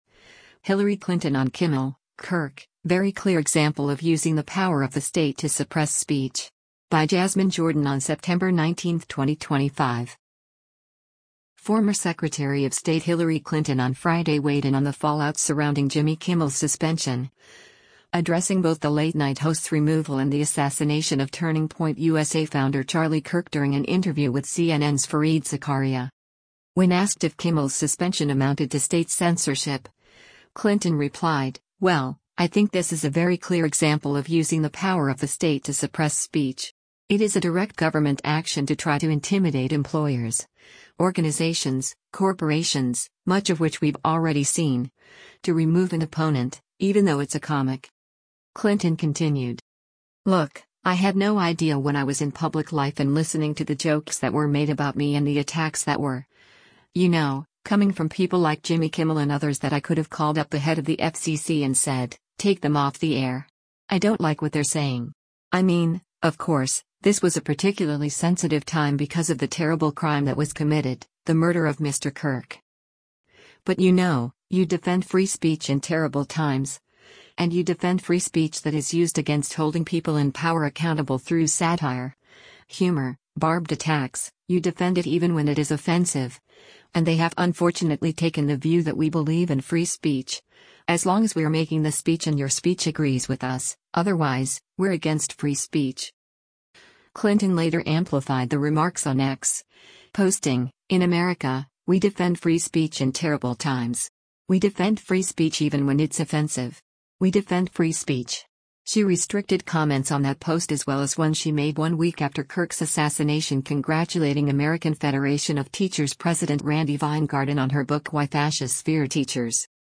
Former Secretary of State Hillary Clinton on Friday weighed in on the fallout surrounding Jimmy Kimmel’s suspension, addressing both the late-night host’s removal and the assassination of Turning Point USA founder Charlie Kirk during an interview with CNN’s Fareed Zakaria.